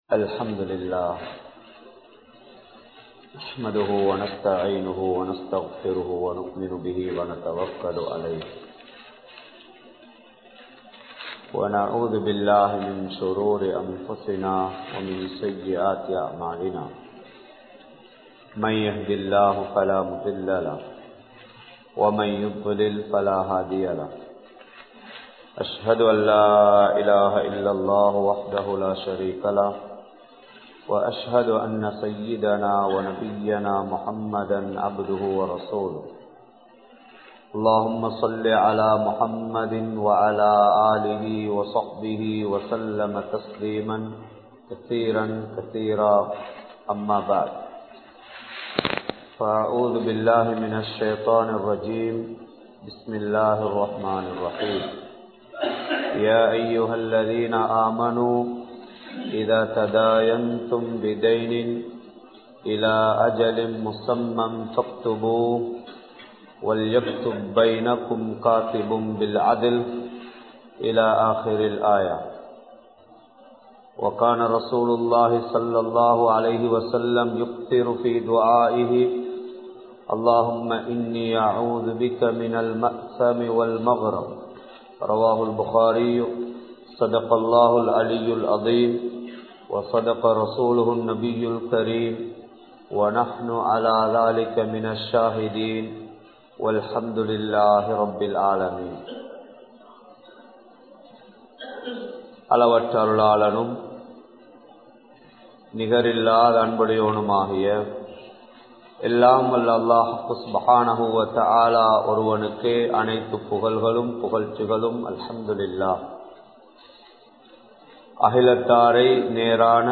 Islamiya Paarvaiel Kadan (இஸ்லாமிய பார்வையில் கடன்) | Audio Bayans | All Ceylon Muslim Youth Community | Addalaichenai